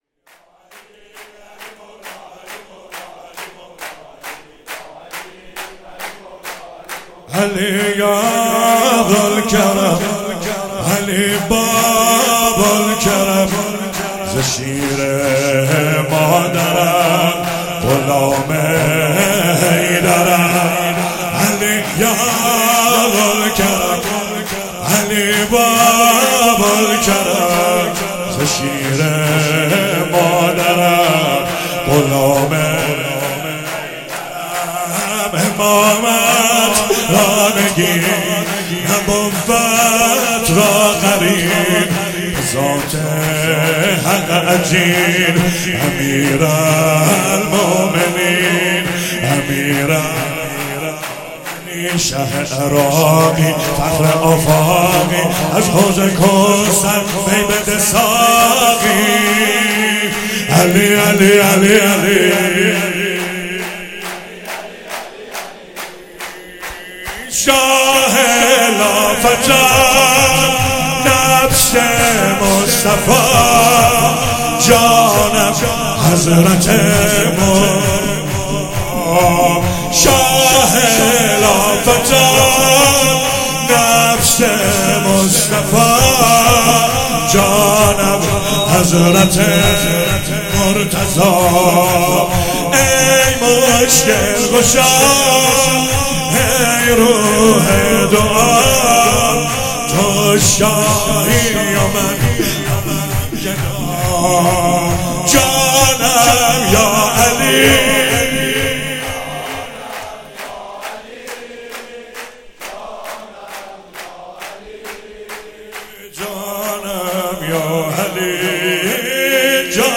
مراسم عید غدیر 16 شهریور 96
چهاراه شهید شیرودی حسینیه حضرت زینب (سلام الله علیها)
شور